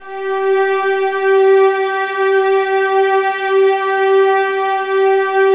VIOLINS .1-L.wav